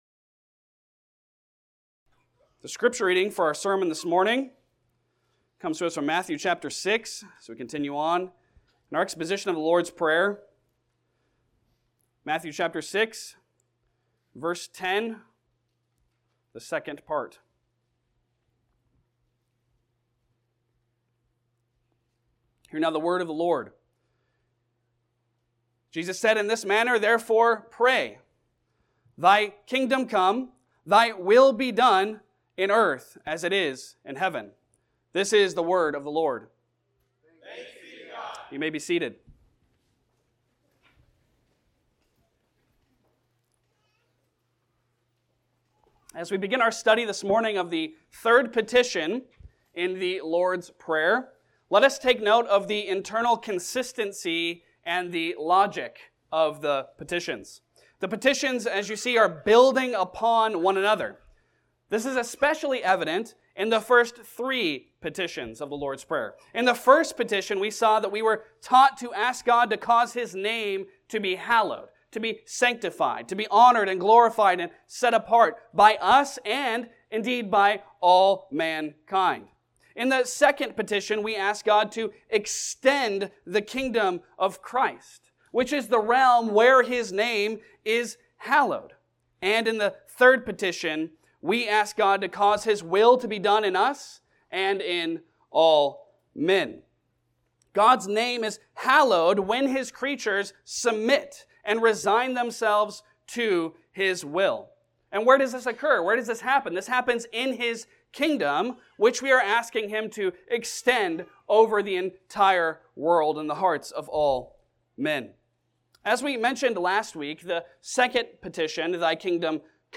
Passage: Matthew 6:10b Service Type: Sunday Sermon